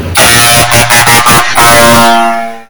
Loud Truck Horn Drive-by Sound Button - Free Download & Play